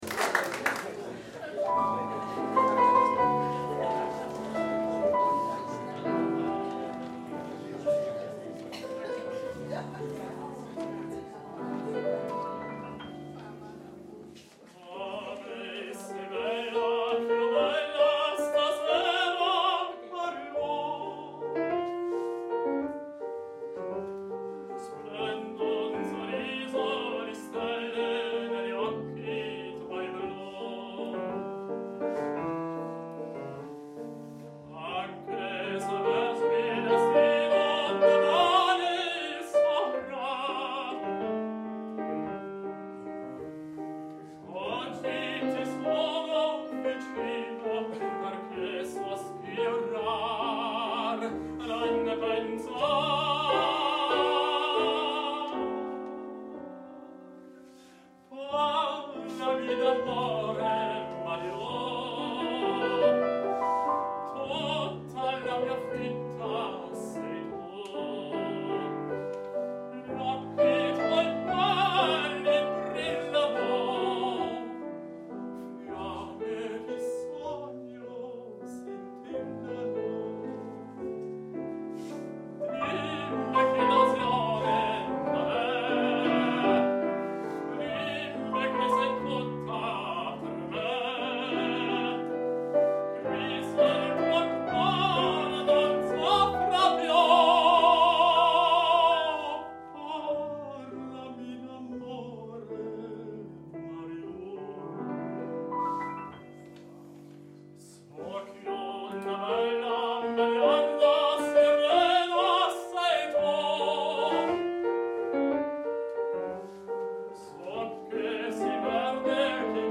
BEGELEIDING LIED EN OPERA/ OPERETTE ARIA’S